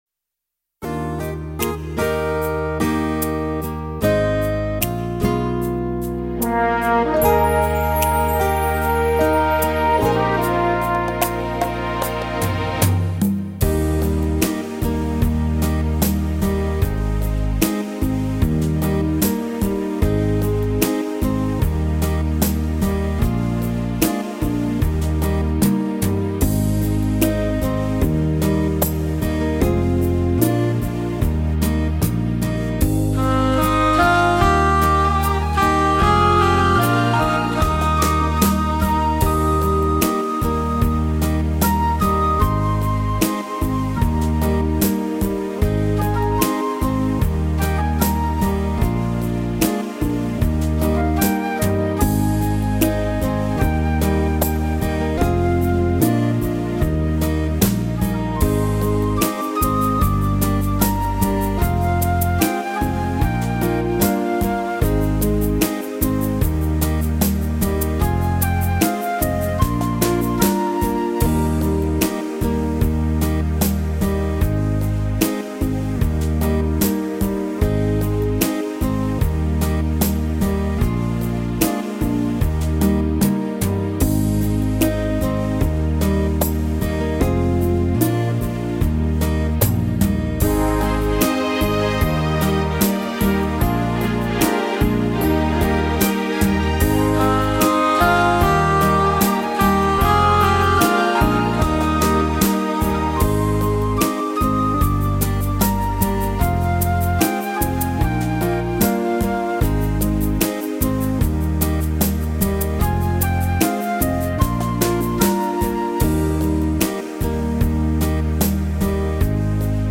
純音樂 instrumental